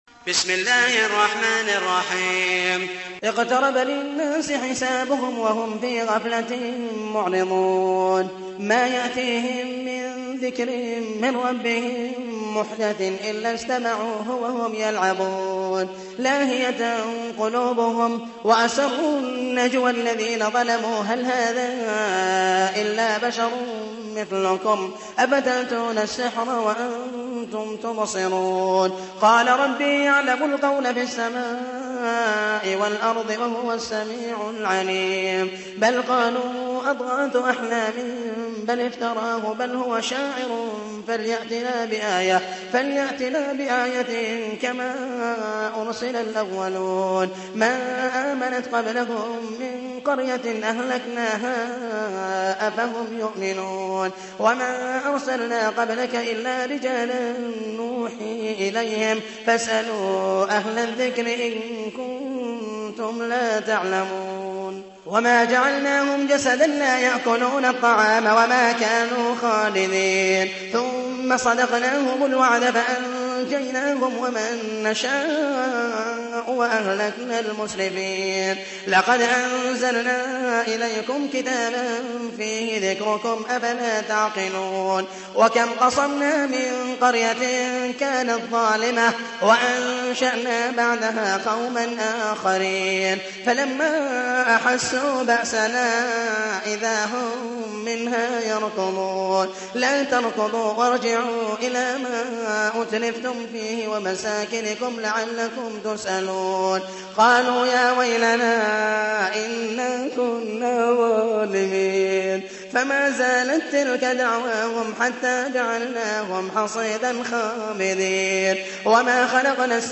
تحميل : 21. سورة الأنبياء / القارئ محمد المحيسني / القرآن الكريم / موقع يا حسين